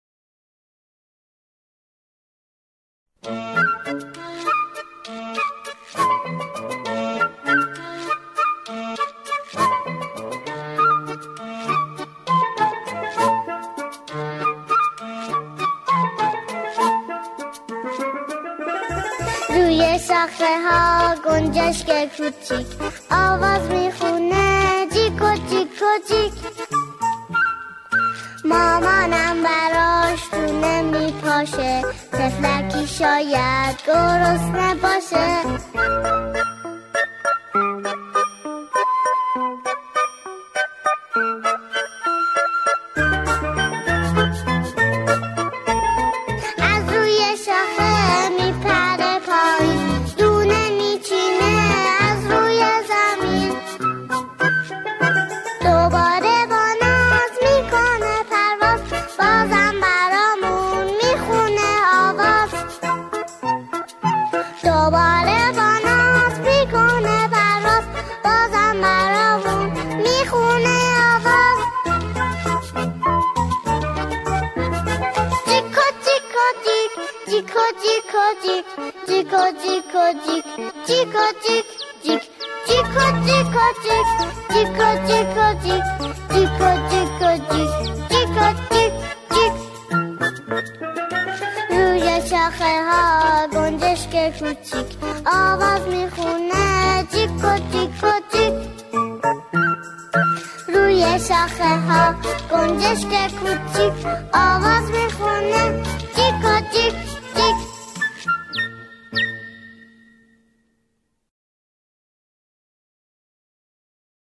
سرود های کودک
خوانندگان، این قطعه را با شعری کودکانه اجرا می‌کنند.